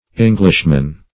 Search Result for " englishman" : Wordnet 3.0 NOUN (1) 1. a man who is a native or inhabitant of England ; The Collaborative International Dictionary of English v.0.48: Englishman \Eng"lish*man\ (-man), n.; pl.